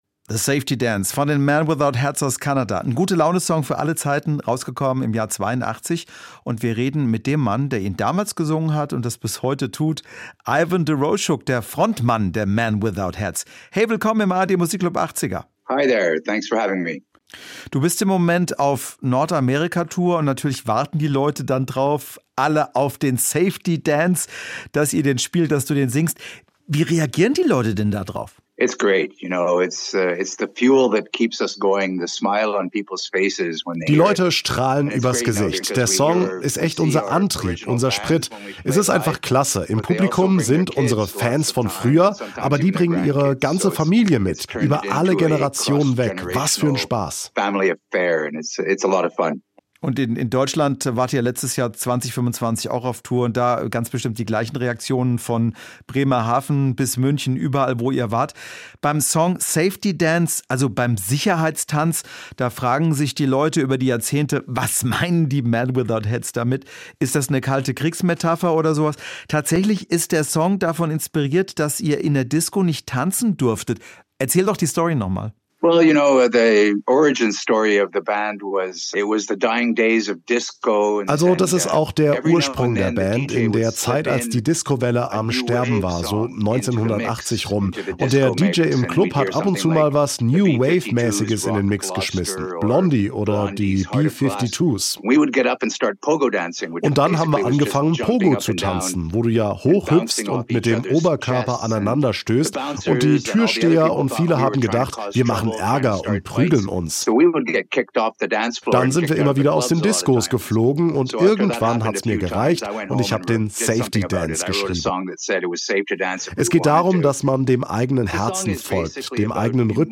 "Men Without Hats"-Sänger im SWR1 Interview
Interview mit